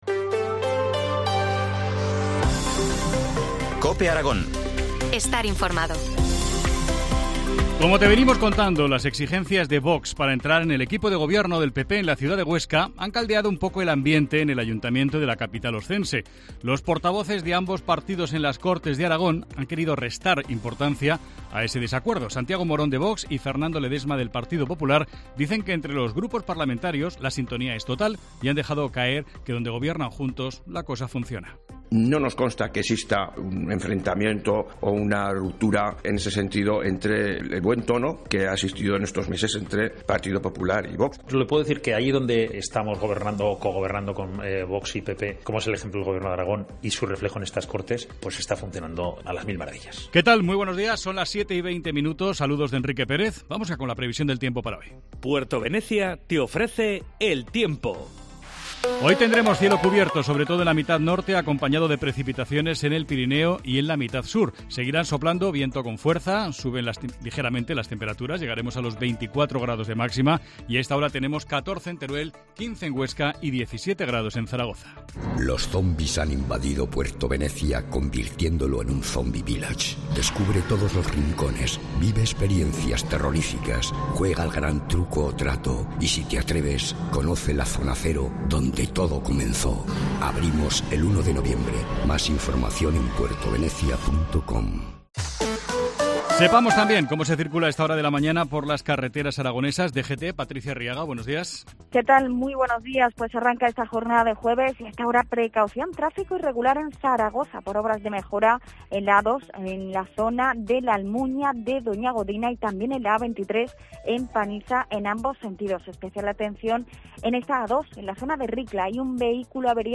AUDIO: Titulares del día en COPE Aragón